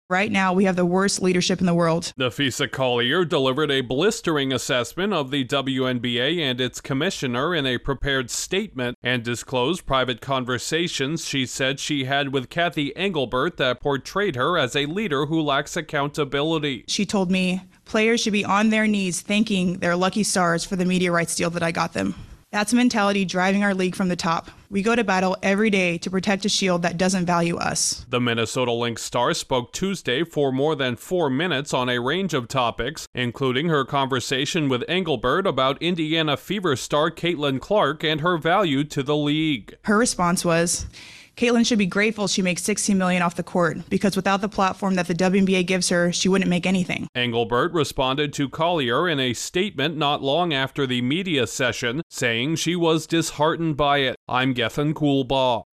The WNBA commissioner is facing criticism from one of the league’s top stars over a lack of player support. Correspondent